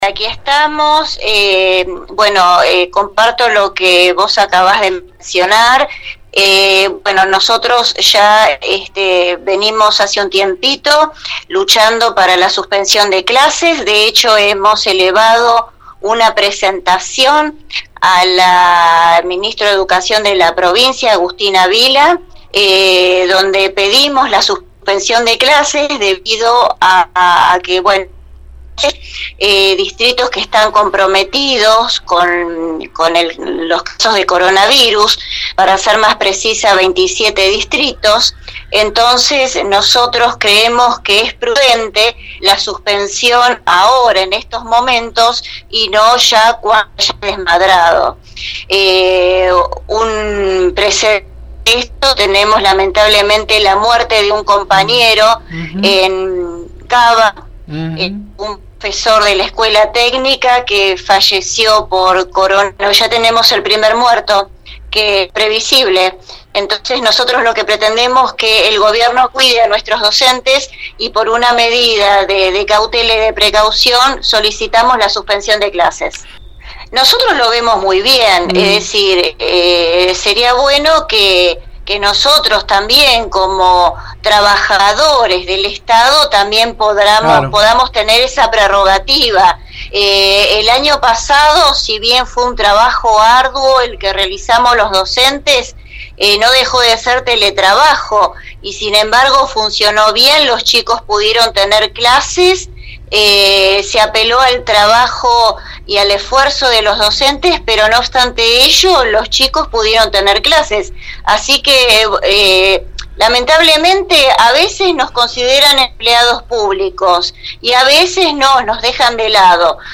consultada en la mañana de la 91.5